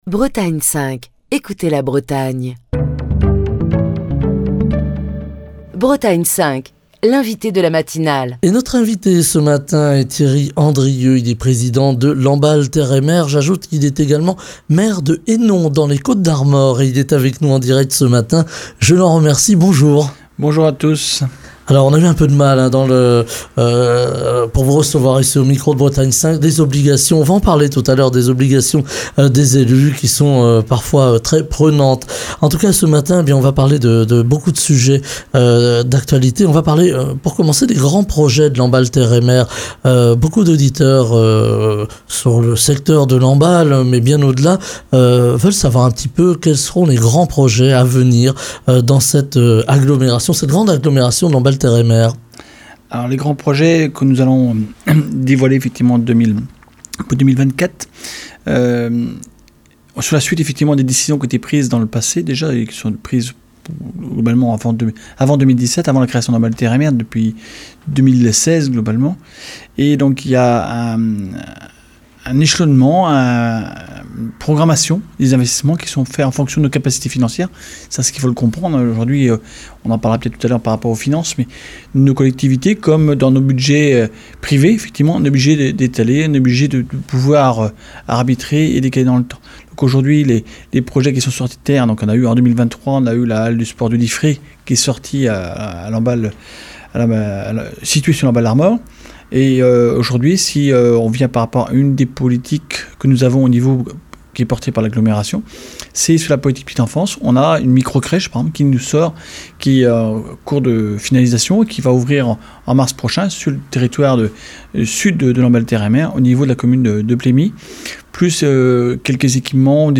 Ce lundi, c'est Thierry Andrieux, président de Lamballe Terre et Mer et maire de Hénon, dans les Côtes d'Armor, qui est l'invité de Bretagne 5 Matin pour évoquer les projets à venir, les finances publiques et le poids des impôts sur les ménages en cette période de forte inflation, les attentes en matière d'aménagement du territoire, l'environnement avec le retour des haies et du bocage et la préservation des terres agricoles alors que nous traversons une crise de l'agriculture, les délégations entre les communes et l'agglo et les difficultés des élus de terrain.